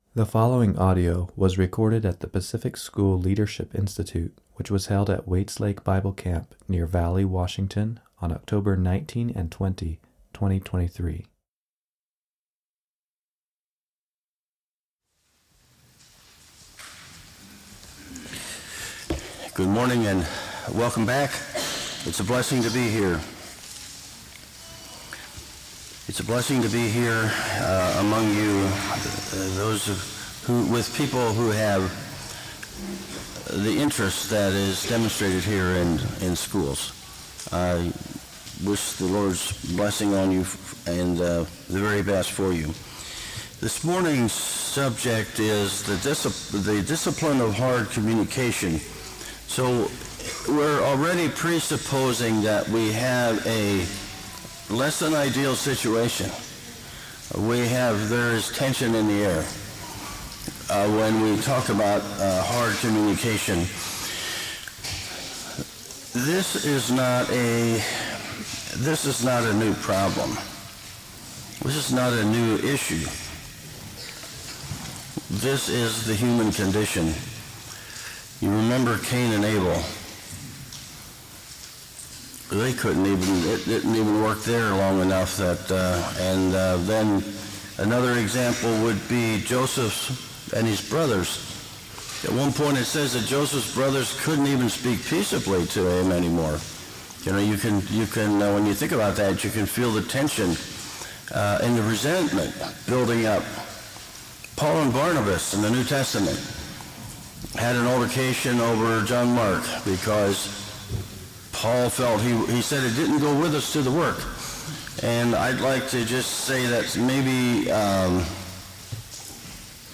Home » Lectures » The Discipline of Hard Communication